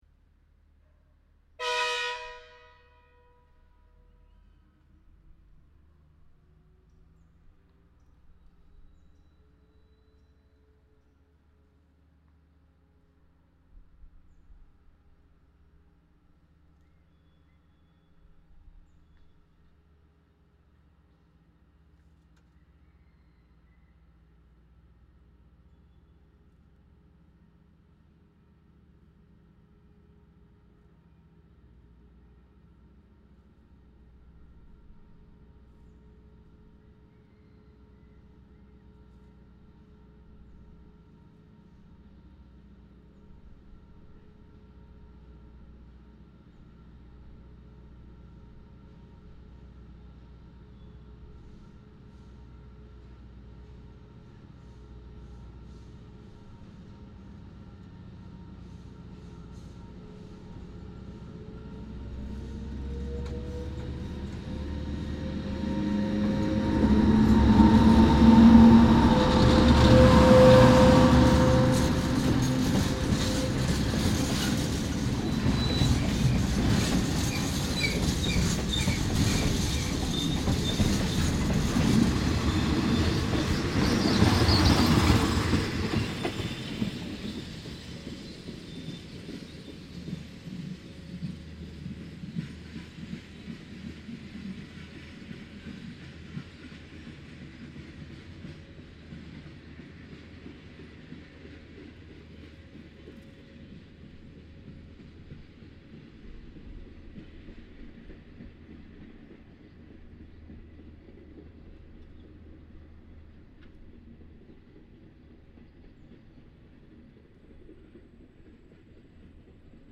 Khun Tan train tunnel, Thailand